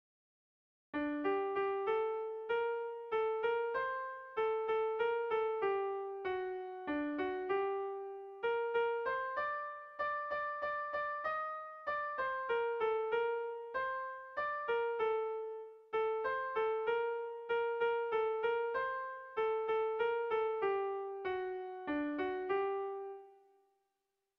Melodías de bertsos - Ver ficha   Más información sobre esta sección
Kontakizunezkoa
A1BA2